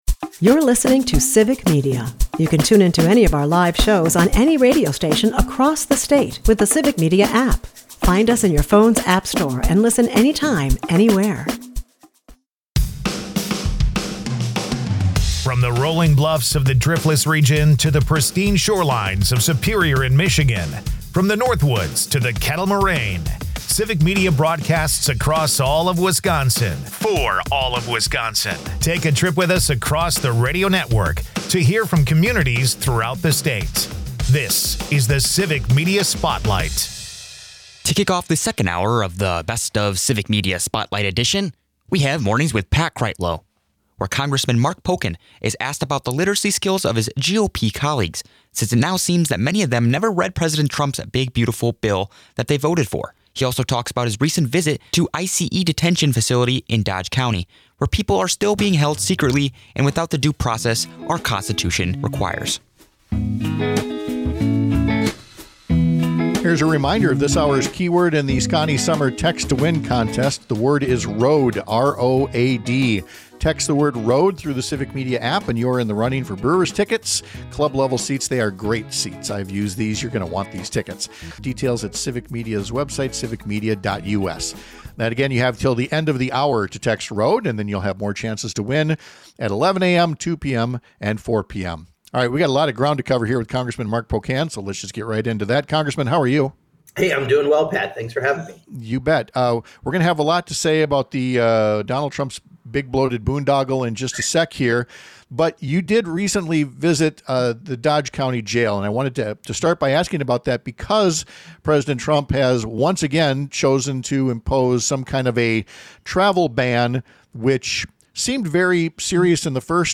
They swap stories about Oshkosh eateries and teenage memories, mixing food, laughter, and a prom-night calzone into a classic late-night radio moment.